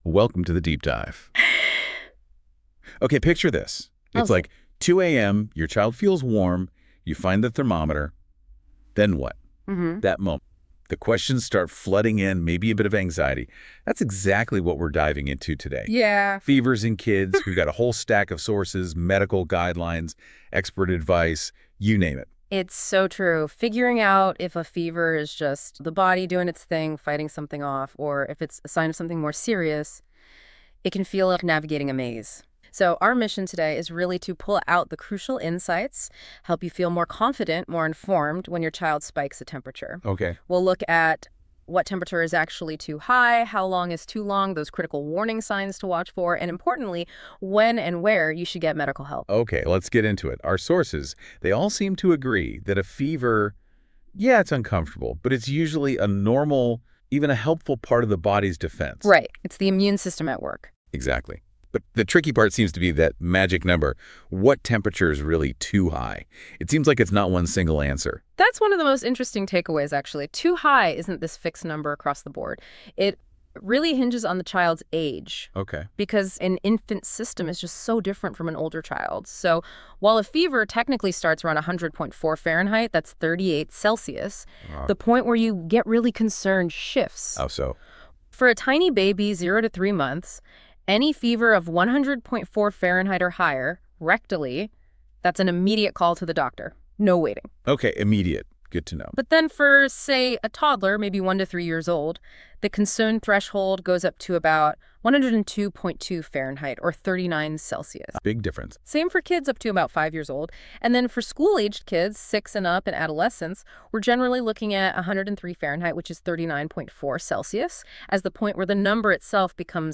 Listen to a discussion about when to take your child to the doctor for a fever Signs you should take your child to a doctor for a fever Contact a doctor immediately if your baby is younger than 3 months and develops a fever.